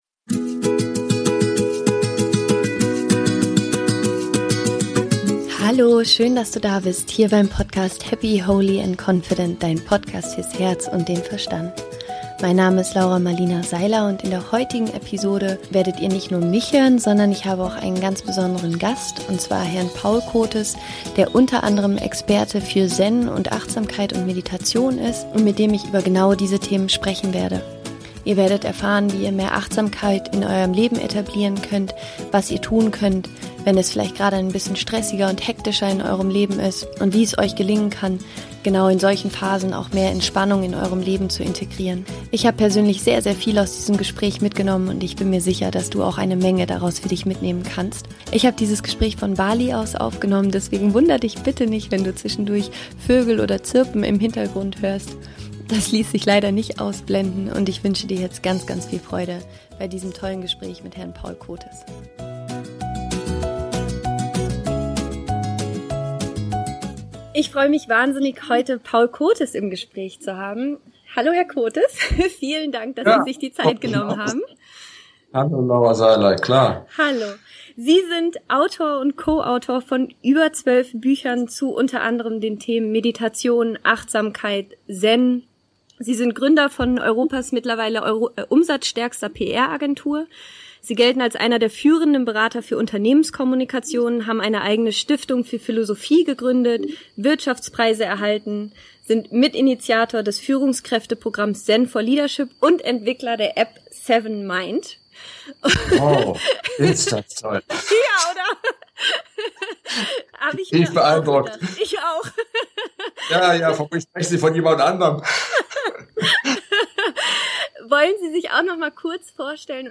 Innere Ruhe und Entspannung finden - Interview Special